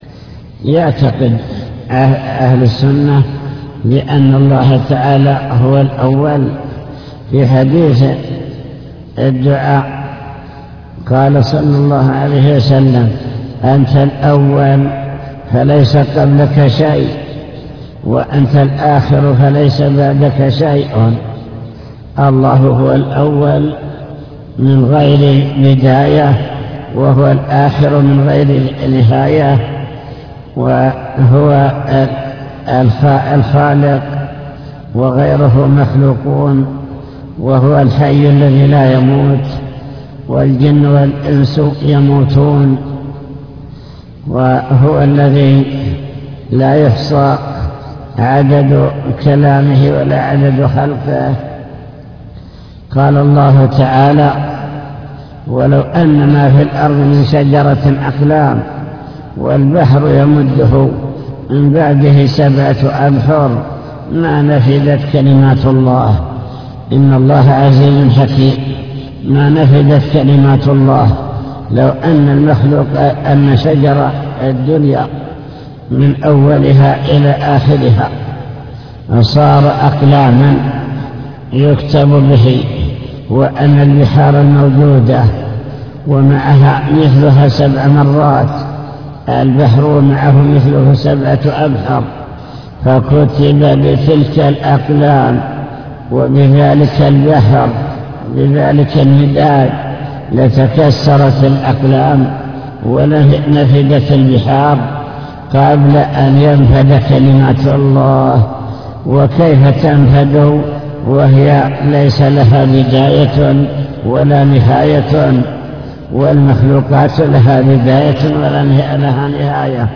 المكتبة الصوتية  تسجيلات - كتب  شرح كتاب بهجة قلوب الأبرار لابن السعدي شرح حديث يأتي الشيطان أحدكم فيقول من خلق كذا